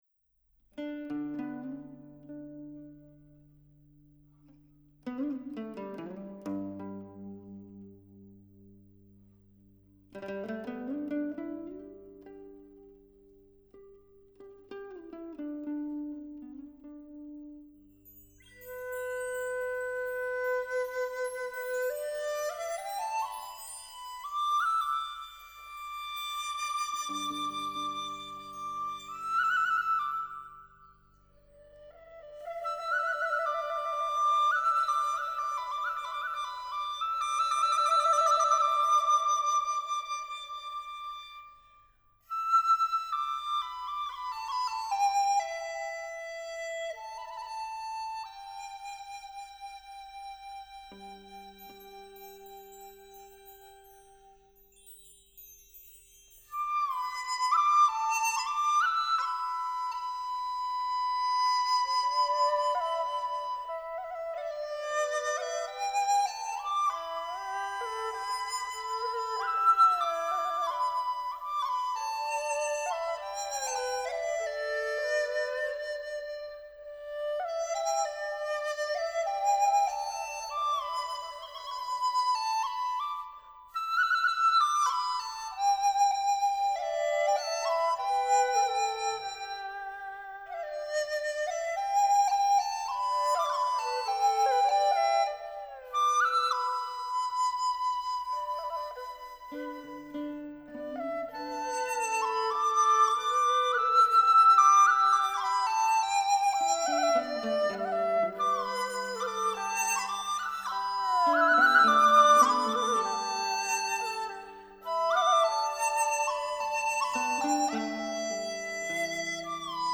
★ 技藝超群的七仙女以音符描繪大唐盛景！